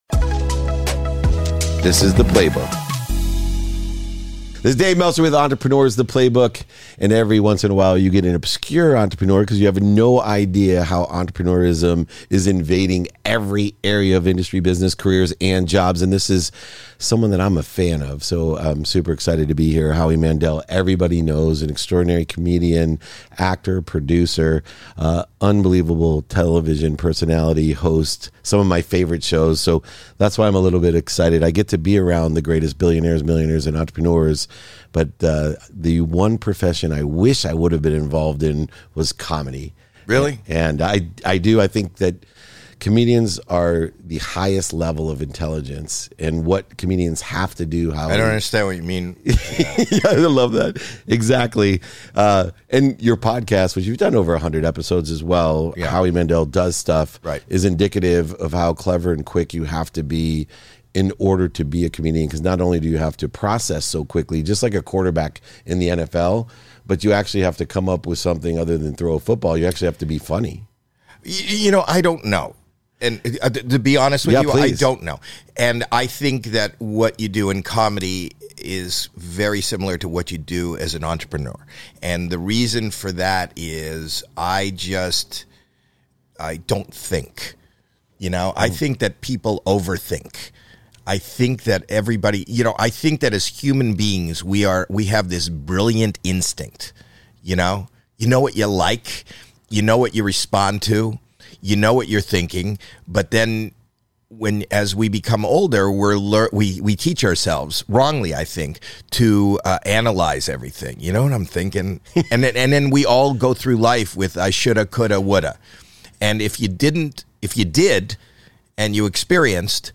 In this conversation with Howie Mandel comedian, television personality, actor, producer, & host of “Howie Mandel Does Stuff”, I learn how a bet led to his comedy career and why he considers his first time performing his greatest success. We also chatted about why people need to avoid overthinking and how he is able to turn his fears into motivation.